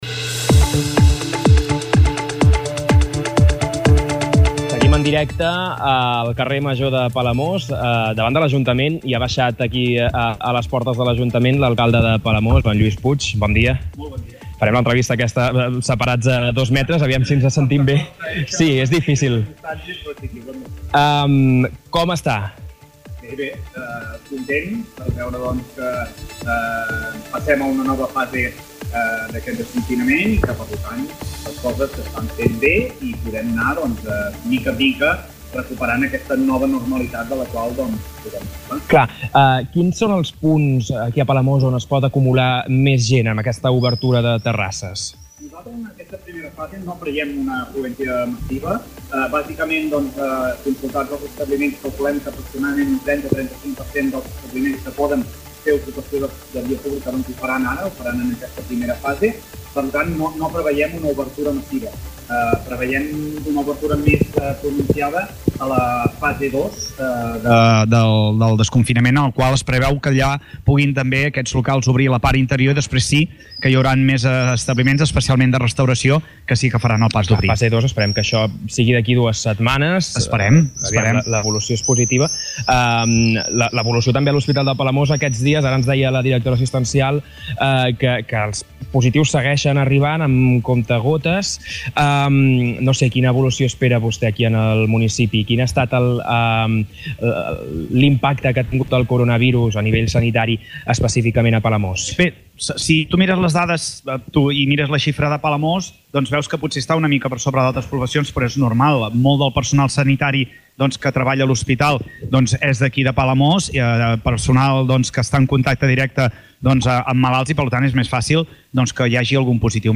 Hem parlat amb l'alcalde de Palamós, Lluís Puig, que ha explicat que només un 30 o 35% dels establiments poden obrir en aquesta fase, i que per tant no preveuen una obertura massiva fins a la fase 2.